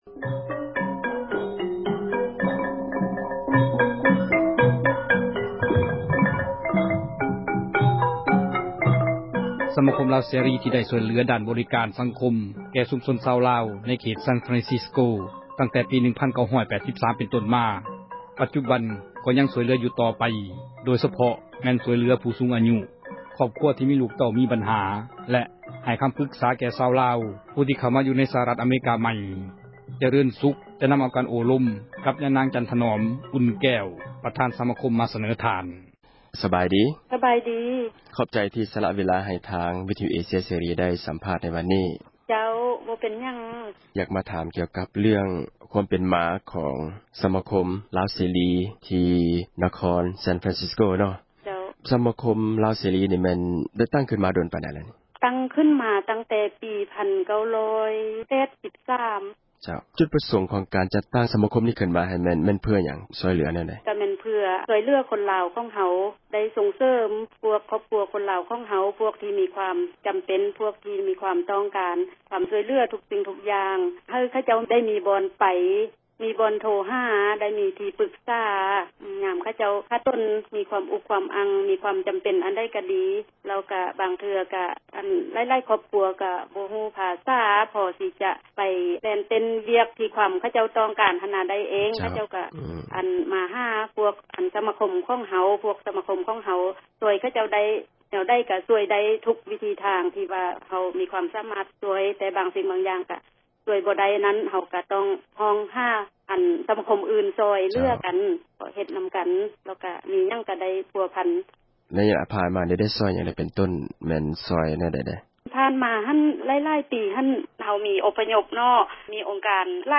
ການໂອ້ລົມ